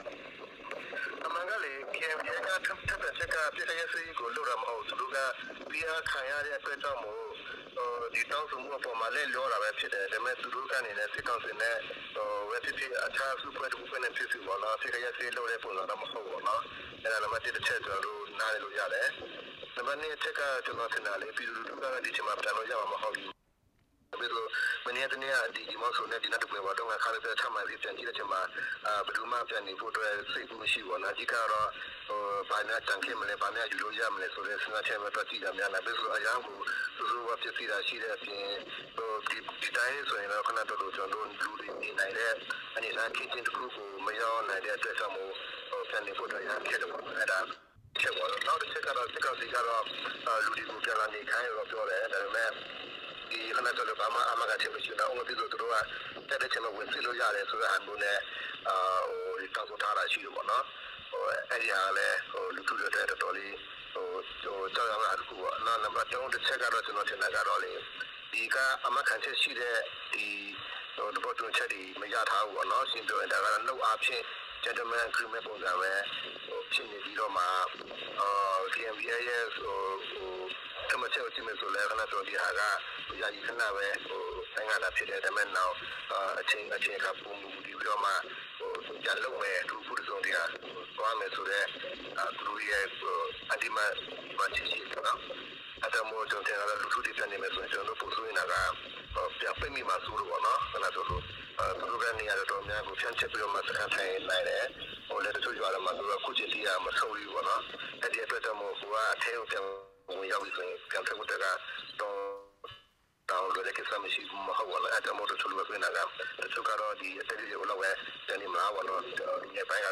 ဒီလိုအခြေအနေအတွင်း တောထဲ ရှောင်ပုန်းနေရတဲ့ စစ်ရှောင်တွေအတွက် အခြေအနေက ဘယ်လို ဖြစ်လာနိုင်သလဲ၊ သူတို့ရဲ့ နေအိမ်မှာ ပြန်လည် နေထိုင်ဖို့ ဖြစ်နိုင်သလား ဆိုတာနဲ့ ဆက်စပ်ပြီး စစ်ရှောင်တွေကို အကူအညီပေးနေတဲ့ တာဝန်ရှိသူ တစ်ဦးနဲ့ မေးမြန်းထားပါတယ်။